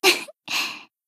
BA_V_Noa_Battle_Shout_3.ogg